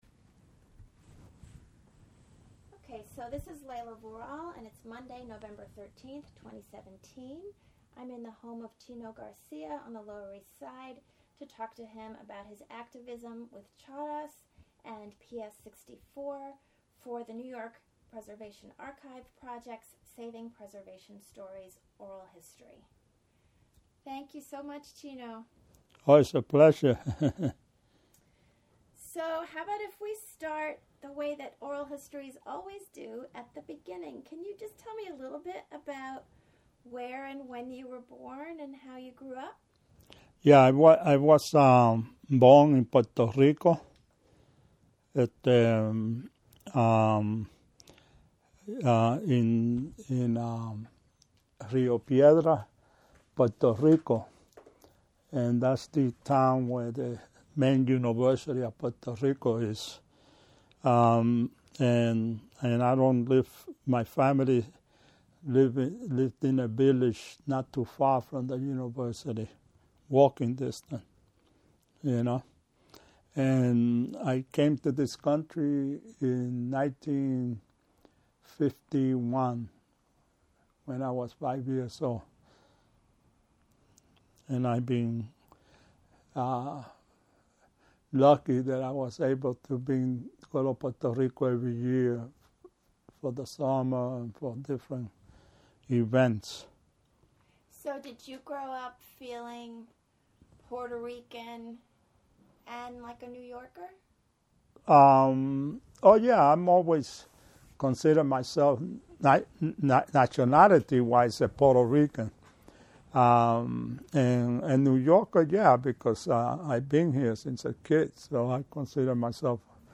This oral history, as well as CHARAS’ papers, are available at the Hunter College Center for Puerto Rican Studies.